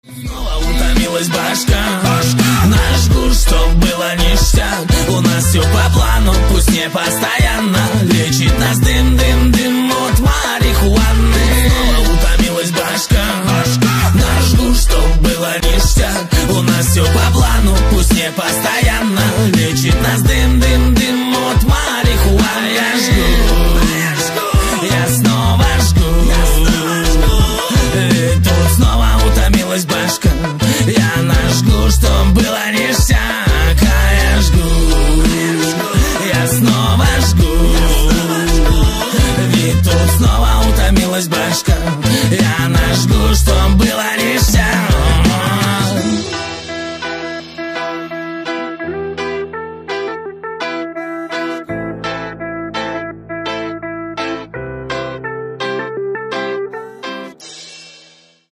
• Качество: 256, Stereo
гитара
мужской вокал
русский рэп